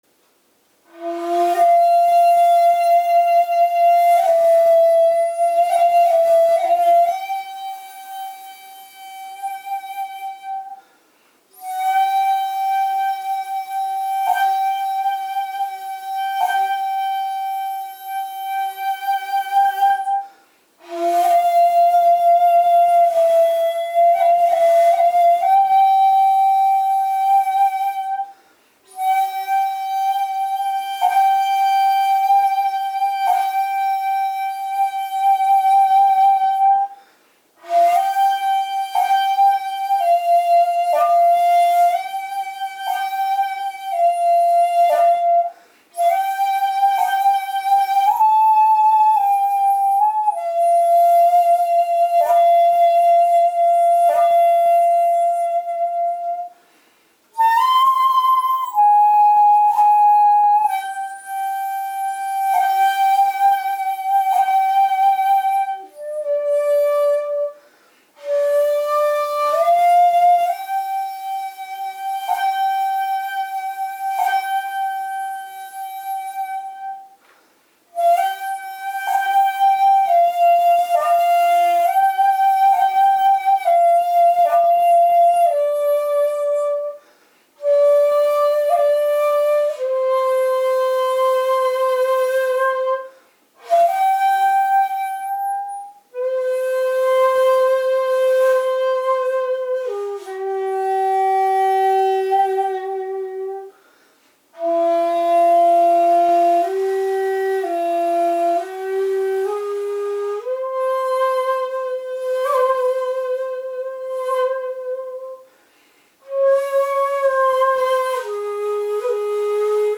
また音楽的に吹いていて面白い「下り葉の曲」はこの時代の人も「戯れた」（楽しい）外曲として